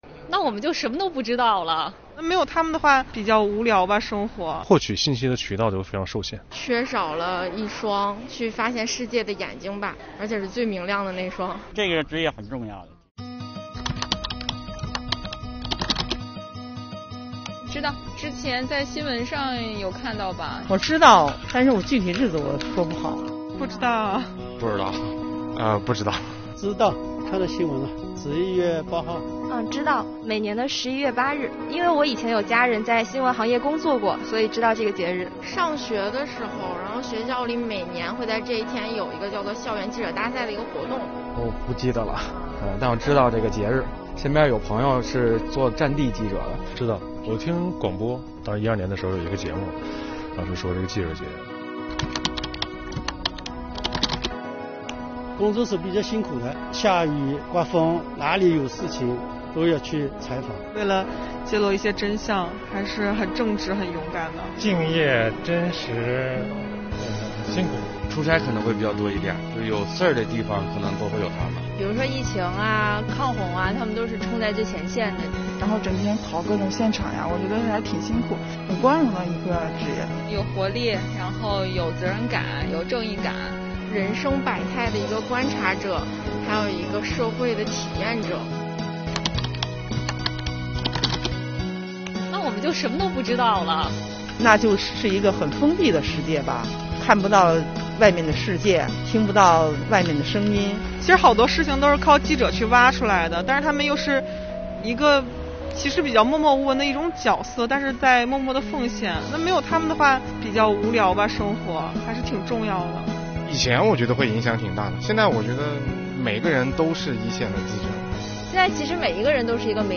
记者节前夕，我们带着这些问题来到北京金融街、莲花池公园、国家税务总局北京市东城区税务局第一税务所等地方，随机采访了不同年龄、不同性别、不同行业的人，快快跟随镜头，揭秘他们的答案↑↑↑